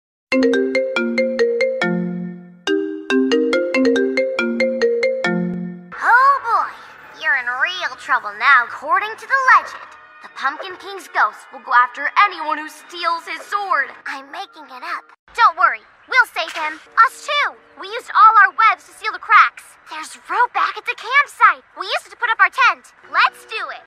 📌 Disclaimer: This is a fun fake call and not affiliated with any official character or franchise.